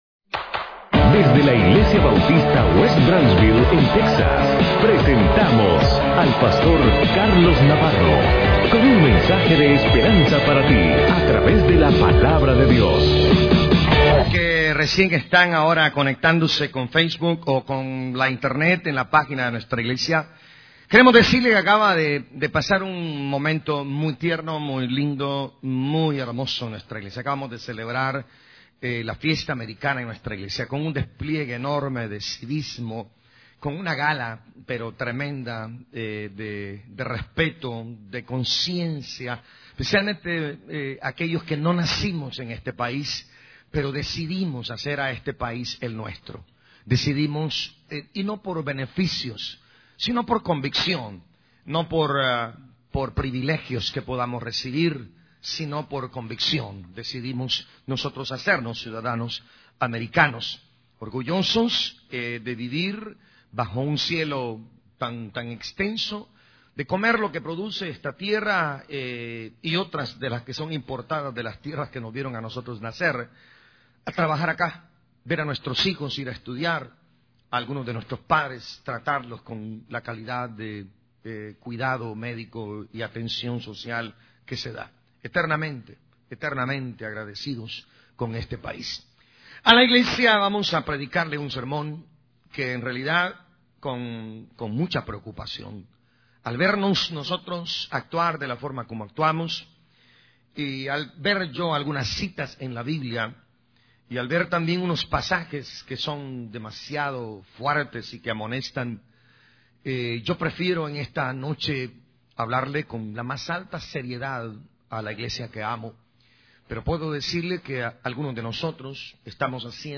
Sermones en audio y vídeo, fotografías, eventos y mucho más ¡Queremos servirle!